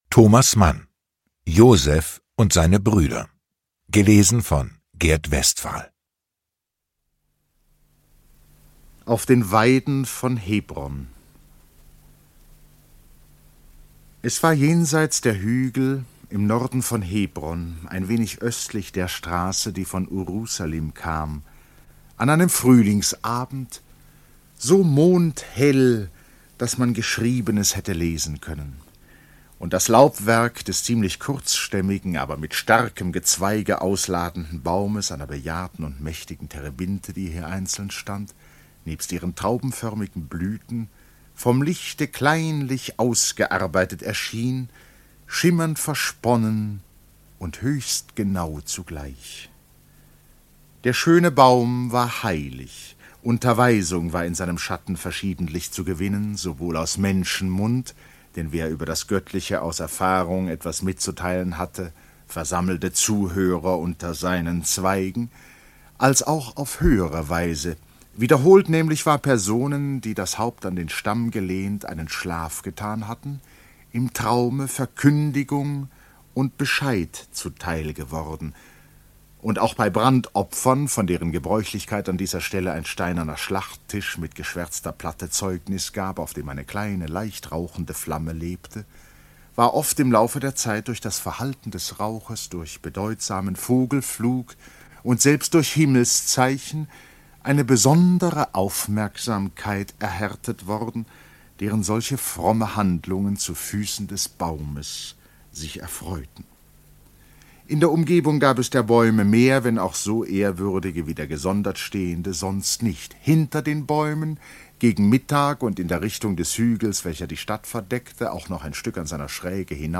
Lesung mit Gert Westphal (3 mp3-CDs)
Gert Westphal (Sprecher)